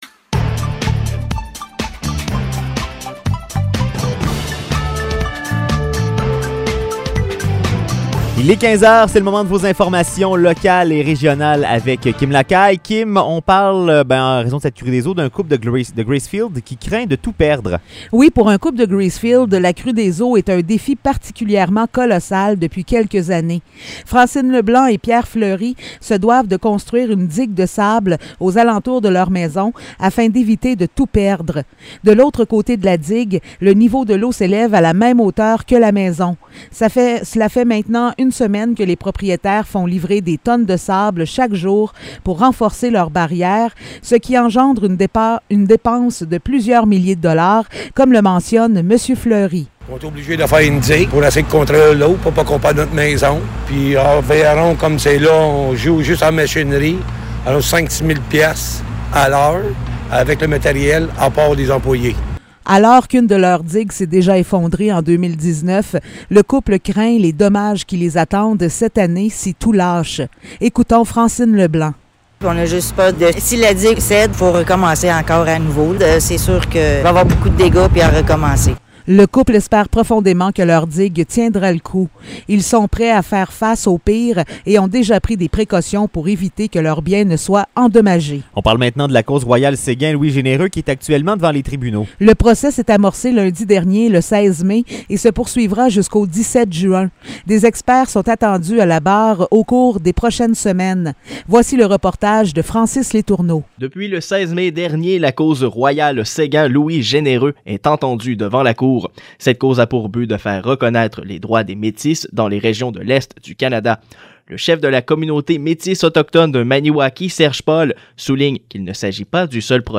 Nouvelles locales - 27 mai 2022 - 15 h